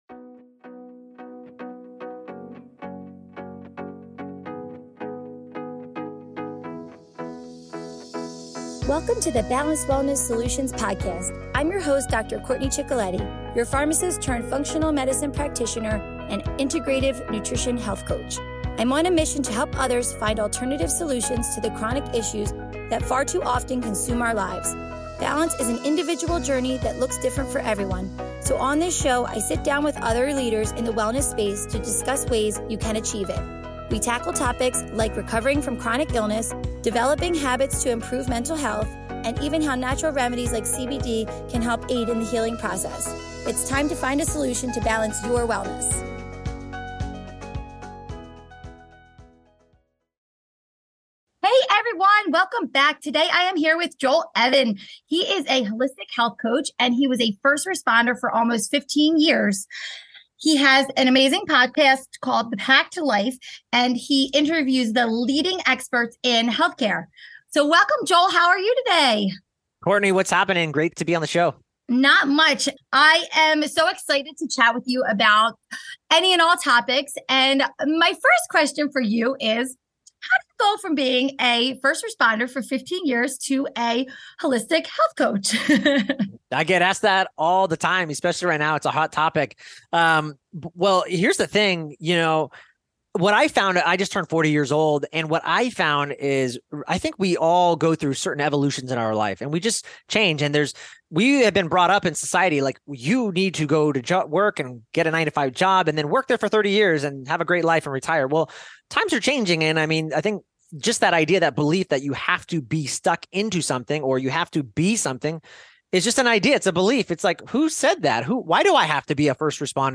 Editing & Sound Mixing